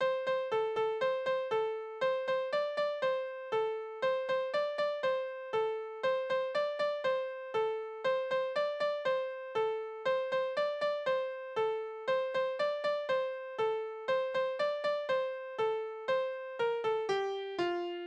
Tonart: F-Dur
Taktart: 4/4
Tonumfang: große Sexte